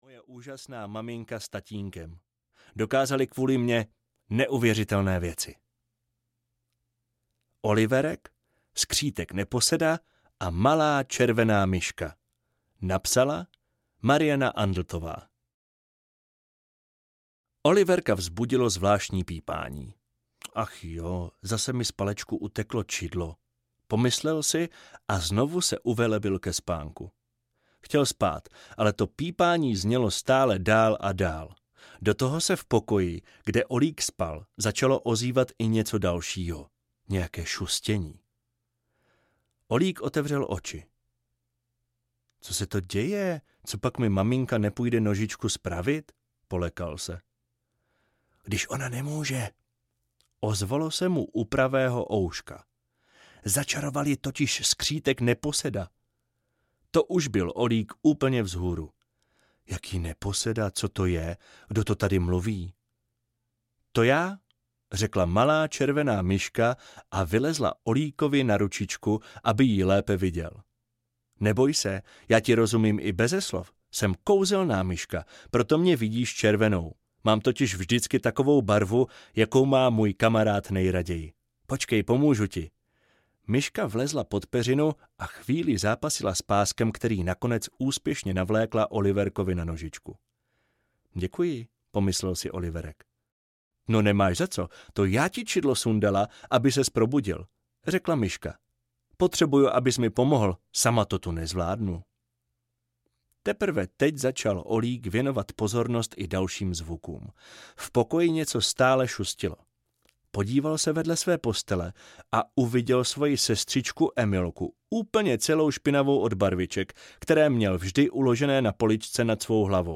Pohádky výjimečných hrdinů audiokniha
Ukázka z knihy